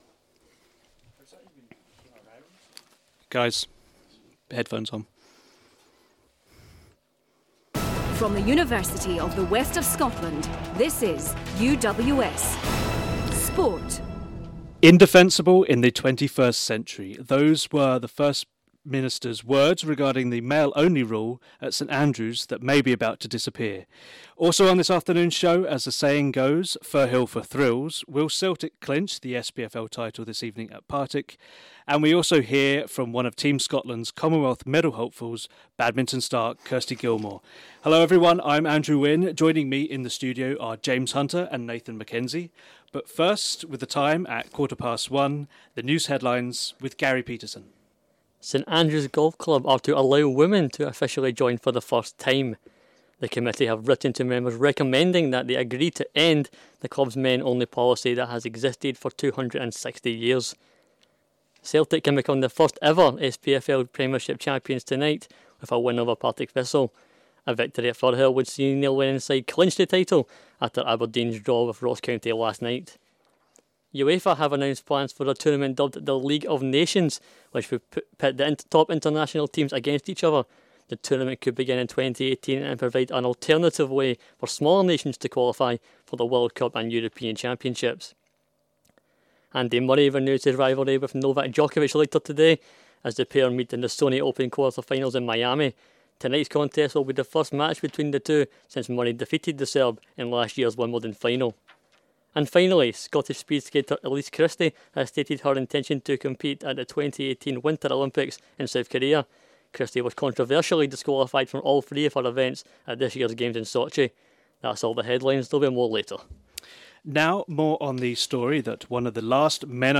A live, 10-minute Sport Update show recorded on UWS Ayr campus, featuring sport headlines and feature stories.